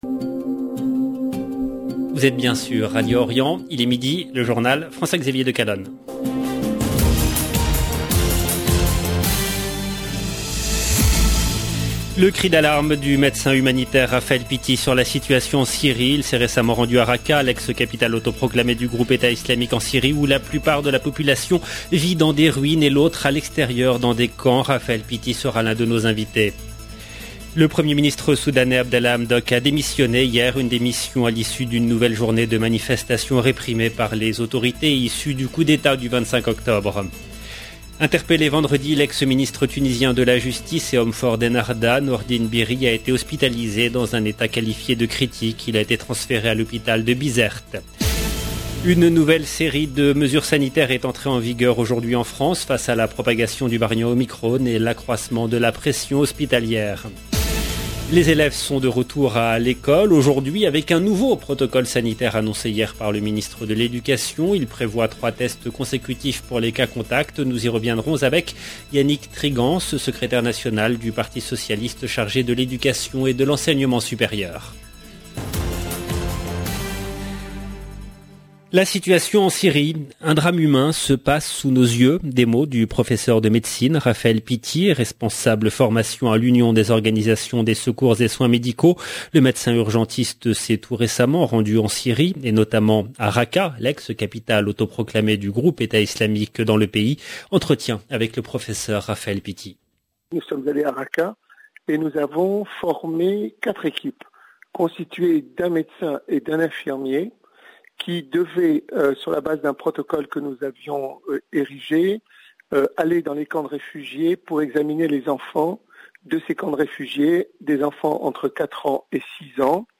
LE JOURNAL EN LANGUE FRANCAISE DE MIDI DU 3/01/22